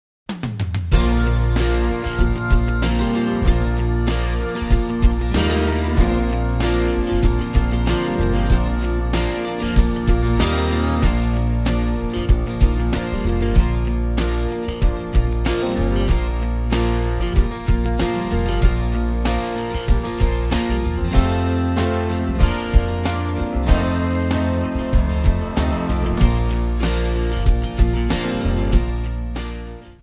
Backing track Karaokes